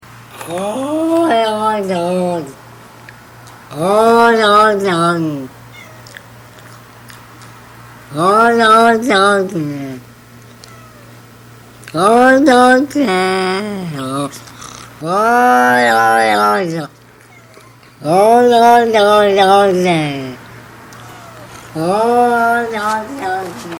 Категория: Смешные реалтоны